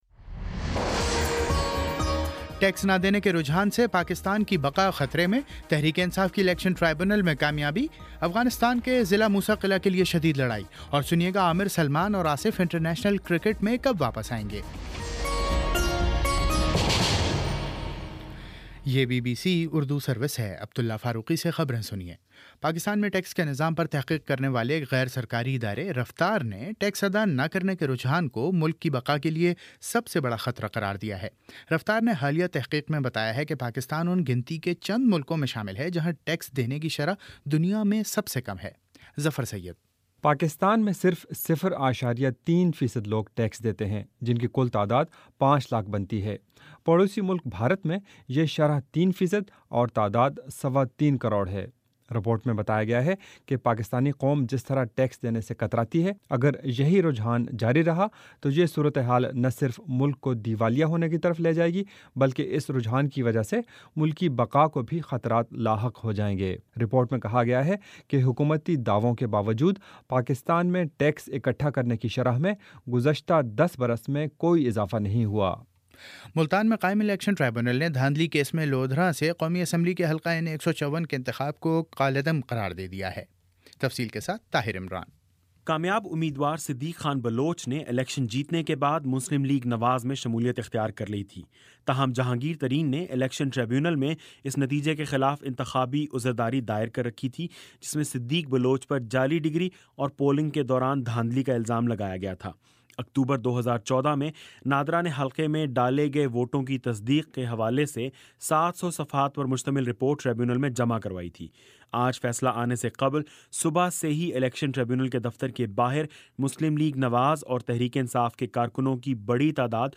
اگست 26: شام سات بجے کا نیوز بُلیٹن